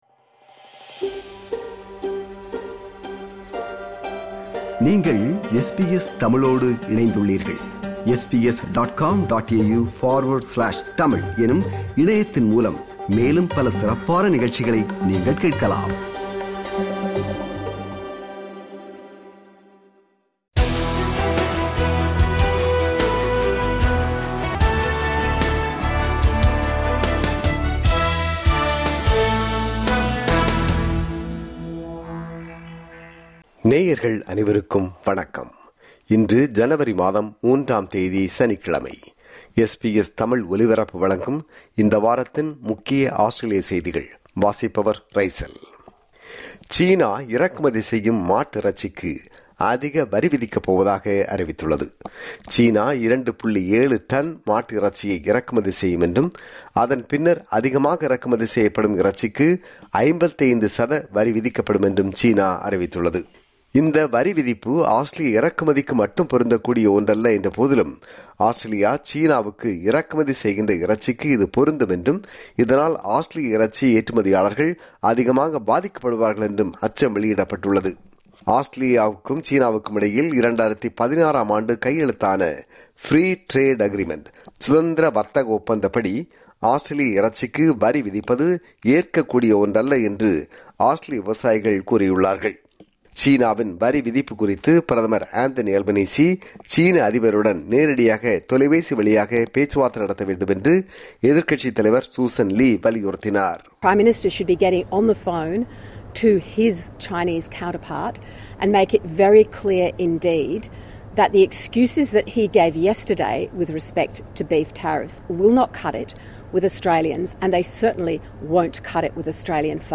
ஆஸ்திரேலியாவில் இந்த வாரம் (28 டிசம்பர் 2025 – 3 ஜனவரி 2026) நடந்த முக்கிய செய்திகளின் தொகுப்பு.